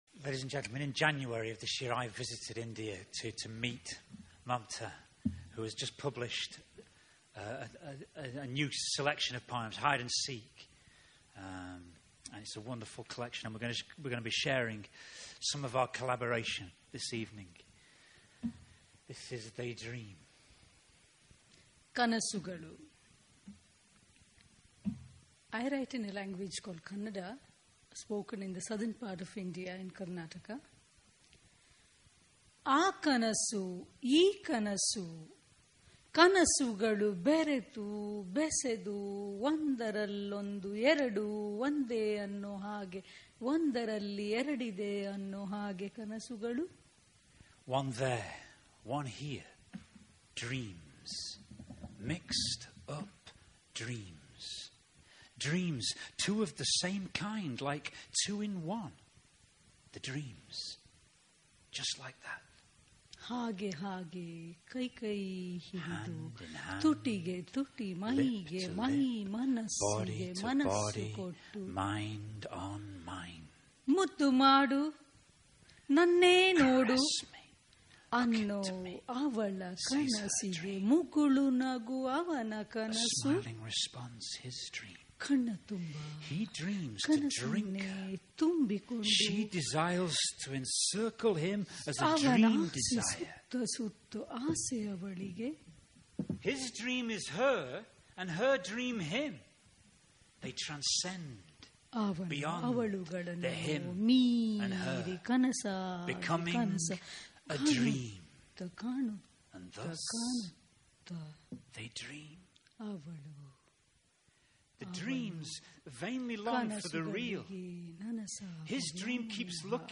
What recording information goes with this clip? Live Ledbury Poetry Festival, 2015